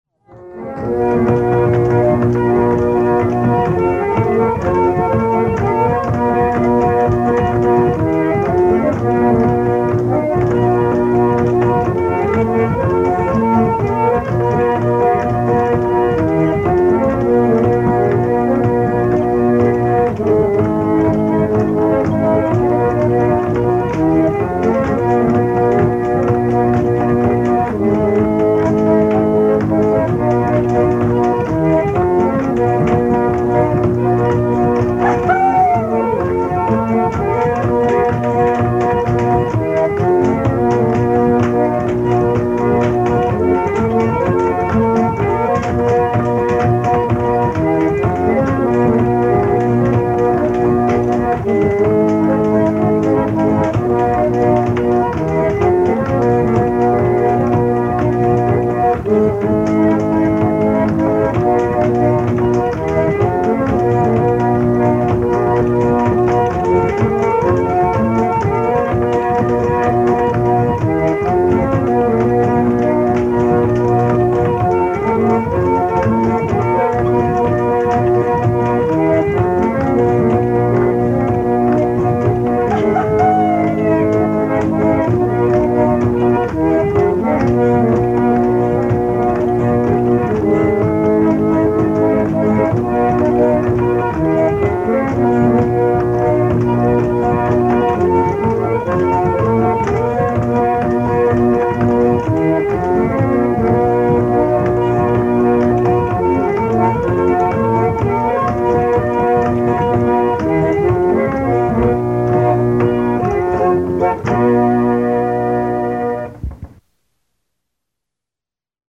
pump organ solo
New Setting Note Accompanied by: unaccompanied